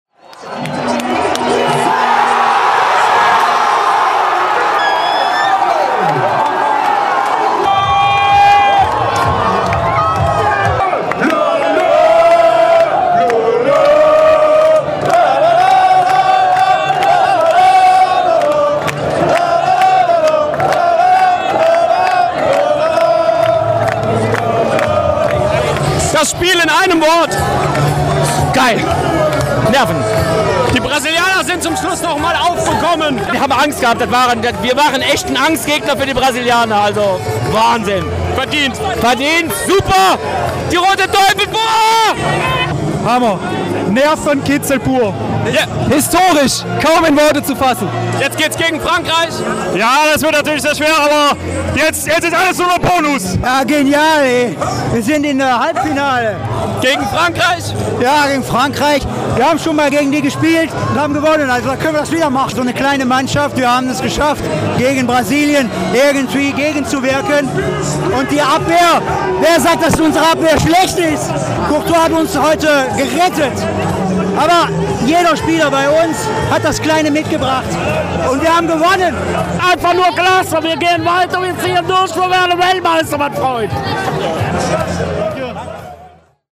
vor Ort